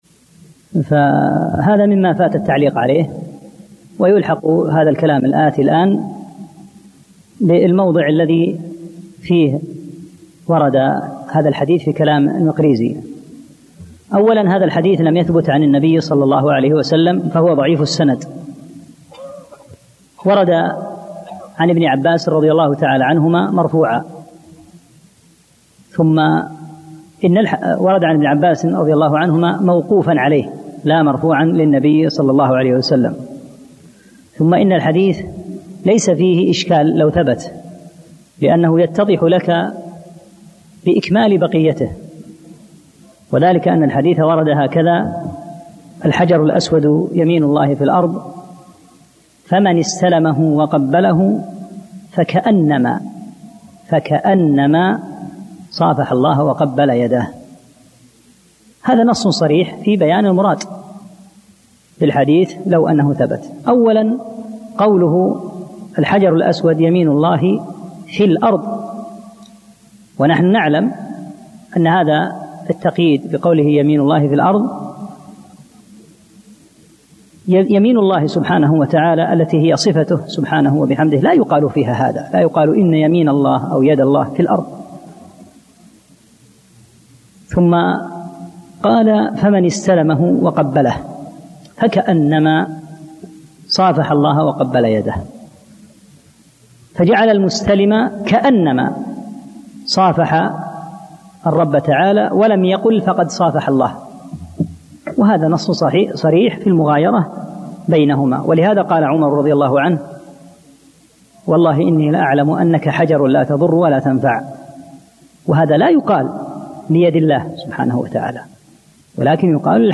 3- الدرس الثالث